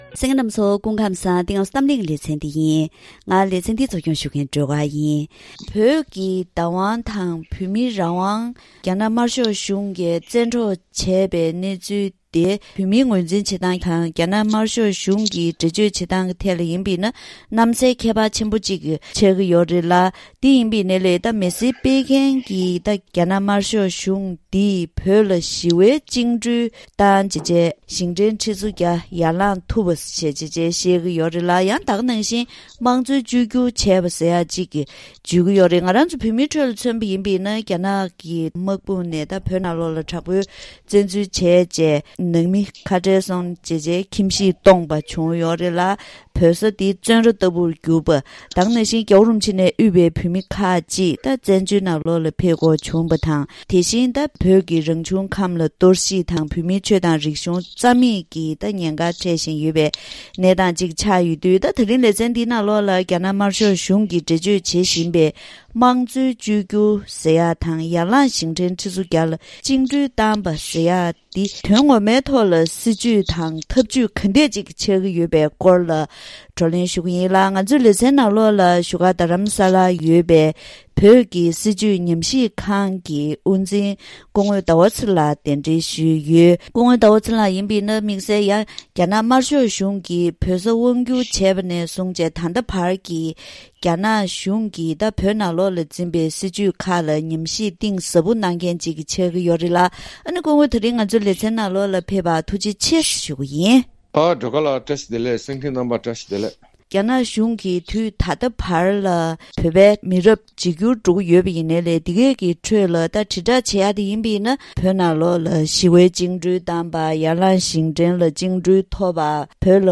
ད་རིང་གི་གཏམ་གླེང་ལེ་ཚན་འདིའི་ནང་། རྒྱ་ནག་དམར་ཤོག་གཞུང་གིས་འགྲེལ་བརྗོད་བྱེད་བཞིན་པའི་དམངས་གཙོའི་བཅོས་བསྒྱུར་ཟེར་བ་དང་ཡར་ལངས་ཞིང་བྲན་ཁྲི་ཚོ་བརྒྱ་ལ་བཅིངས་འགྲོལ་བཏང་པ་ཟེར་བ་འདི་དོན་དངོས་ཐོག་སྲིད་ཇུས་དང་ཐབས་ཇུས་གང་འདྲ་ཞིག་ཆགས་ཀྱི་ཡོད་པའི་སྐོར་ལ་བགྲོ་གླེང་ཞུས་པ་ཞིག་གསན་རོགས་གནང་།